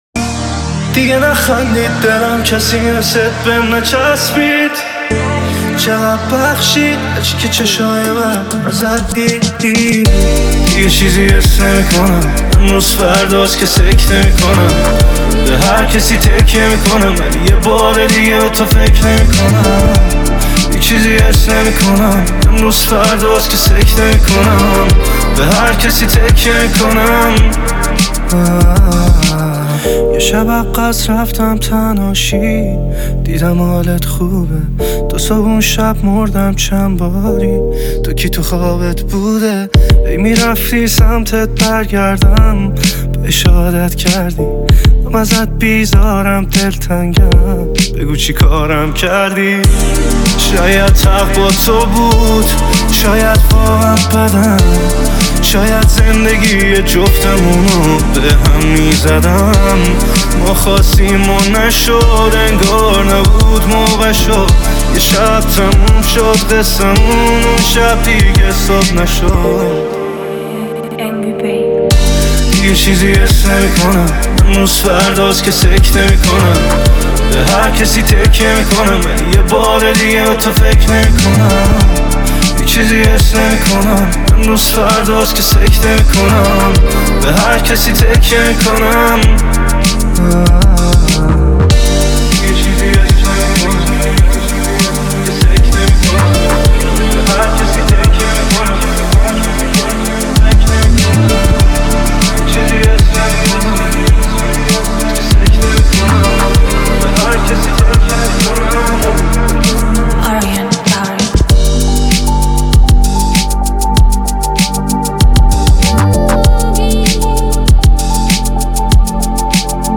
بسیار زیبا و احساسی